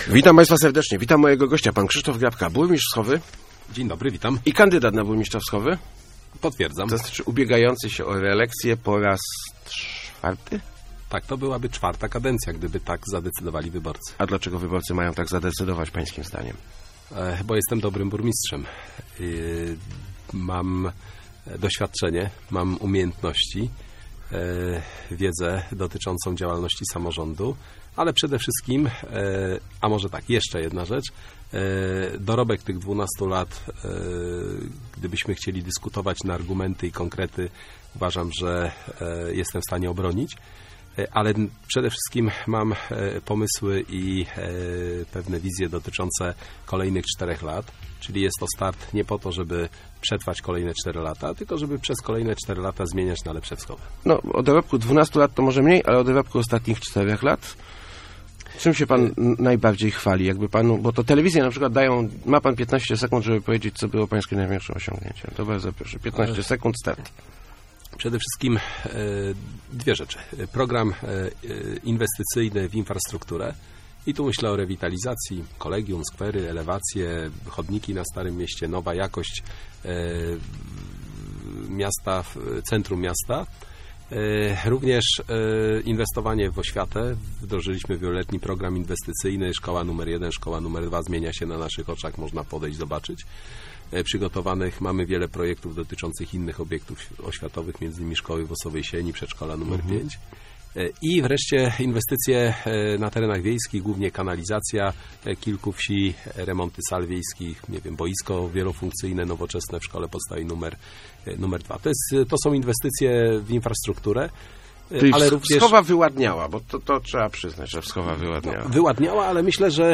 Mam poczucie niedosytu z powodu niewybudowania załadu IKEA - mówił w Rozmowach Elki burmistrz Wschowy Krzysztof Grabka. Zapewnił on jednak, że udało mu się w ostatniej kadencji zrealizować wiele planów, zwłaszcza tych dotyczących rozwoju infrastruktury i inwestycji w oświatę.